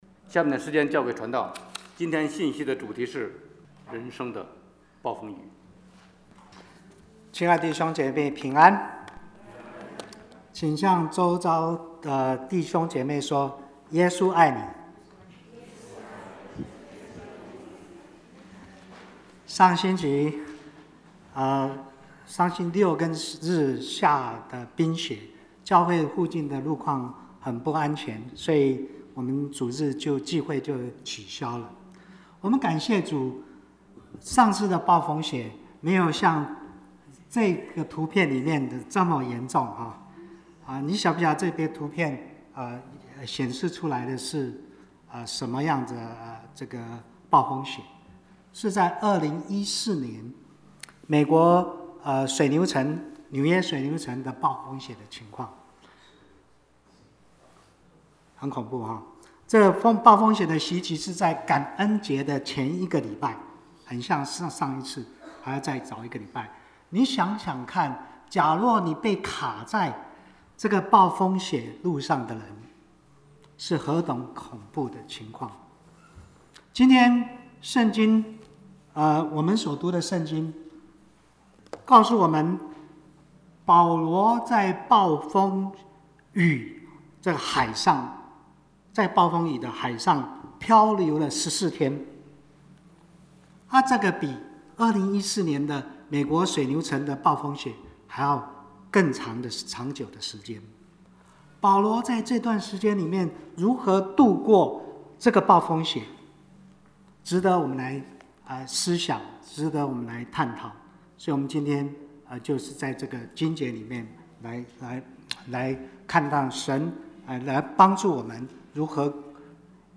Bible Text: Acts 27:14-29 | Preacher: